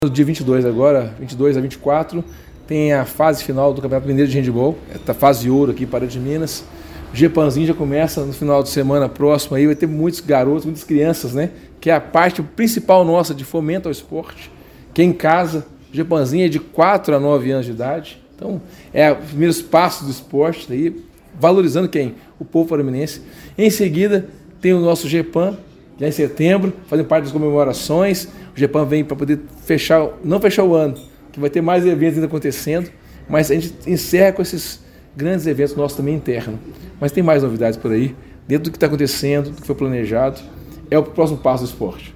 O secretário Paulo Francisdale também anunciou que a agenda esportiva local seguirá intensa, com a fase final do Campeonato Mineiro de Handebol, o Jepamzinho, competição infantil, além dos Jogos Escolares de Pará de Minas (Jepam) em setembro, entre outros: